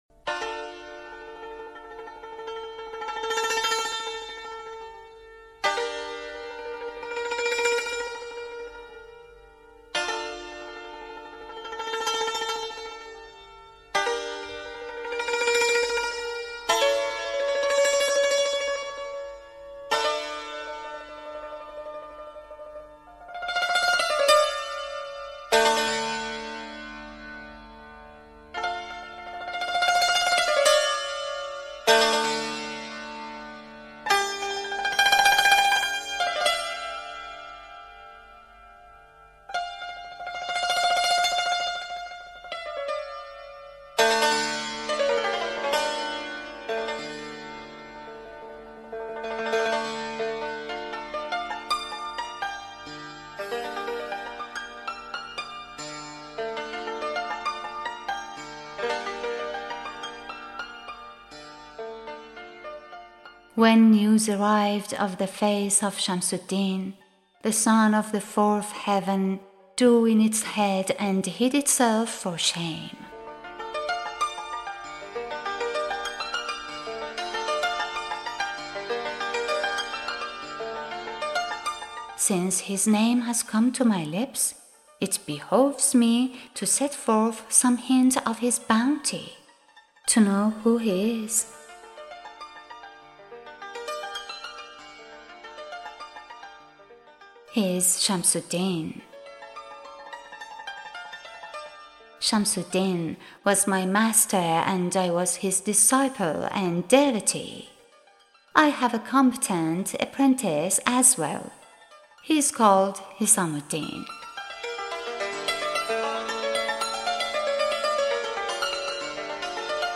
Music by: Ardavan Kamkar